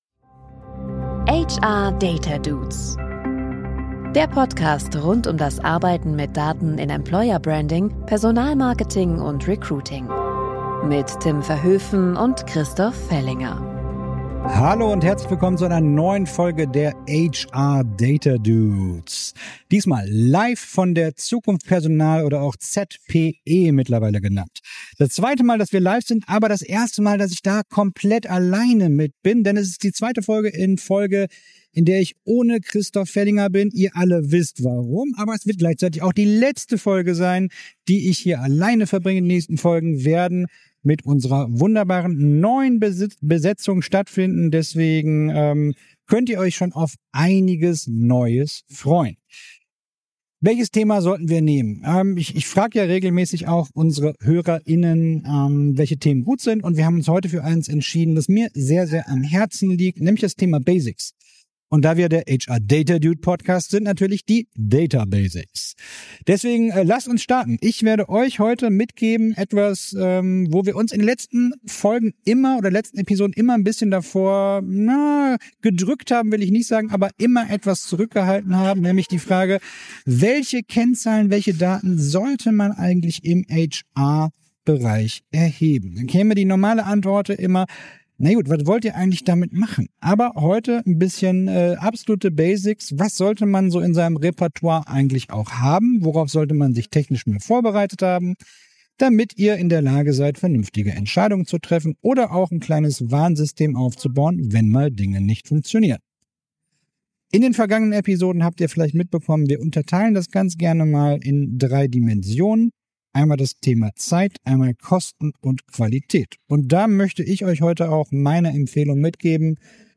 #27: Data Basics - Live von der ZPE ~ HR Data Dudes Podcast